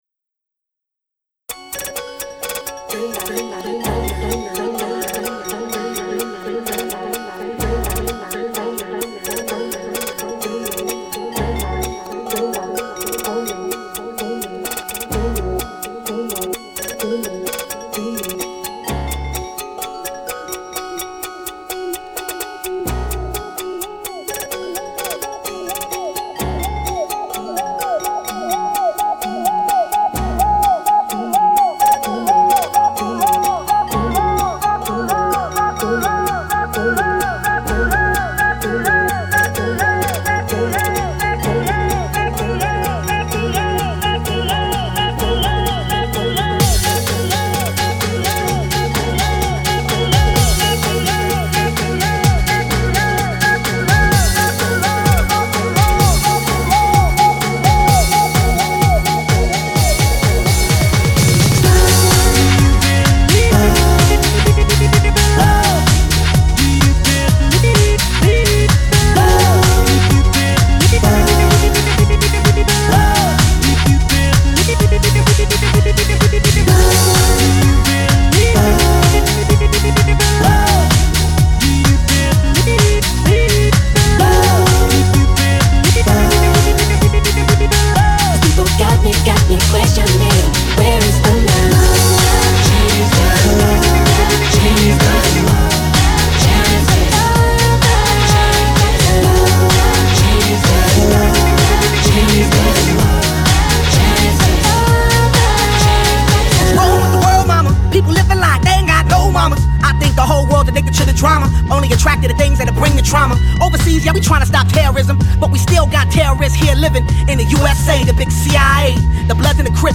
We are still on a mash-up kick.